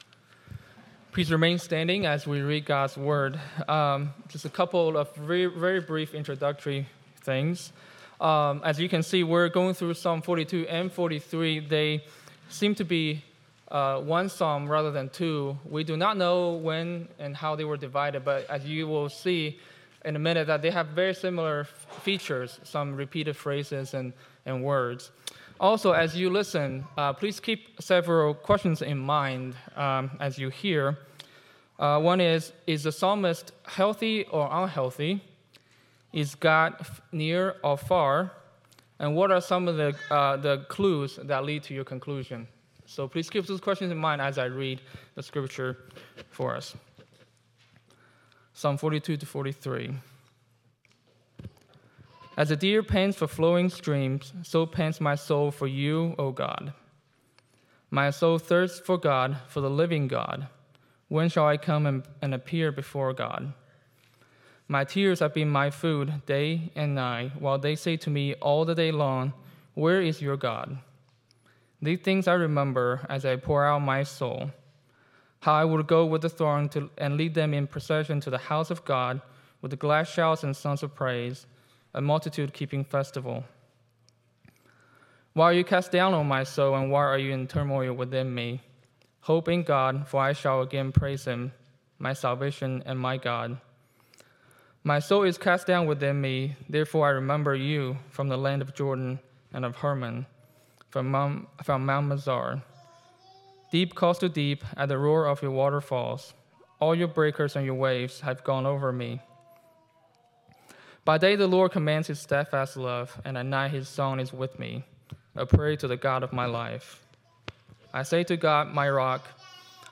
6.29.25 Sermon.m4a